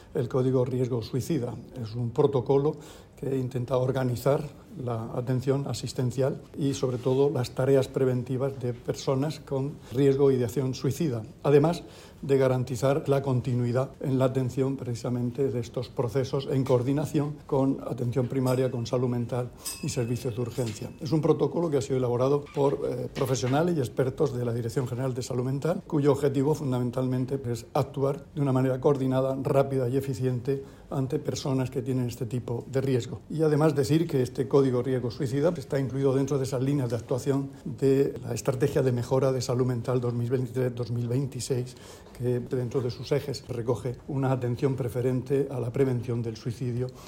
Declaraciones del consejero de Salud, Juan José Pedreño, sobre el nuevo protocolo para prevenir conductas suicidas. [mp3]
El consejero de Salud, Juan José Pedreño presentó hoy en Caravaca de la Cruz el 'Código Riesgo Suicida'